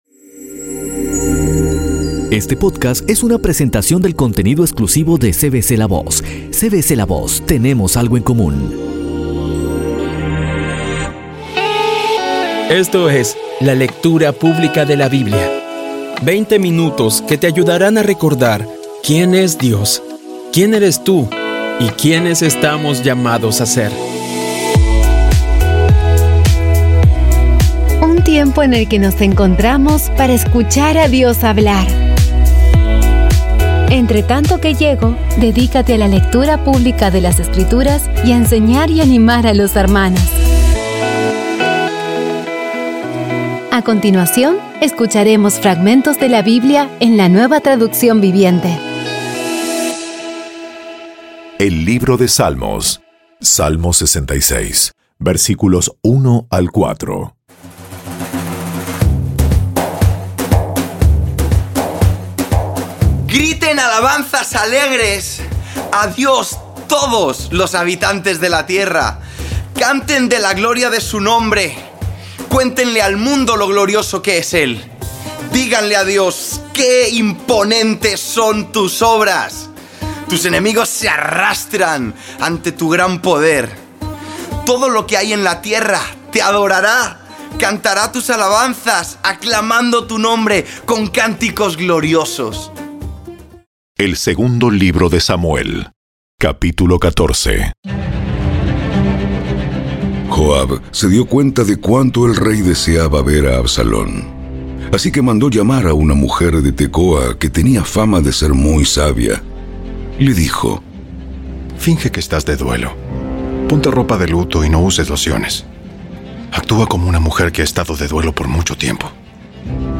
Audio Biblia Dramatizada Episodio 143
Poco a poco y con las maravillosas voces actuadas de los protagonistas vas degustando las palabras de esa guía que Dios nos dio.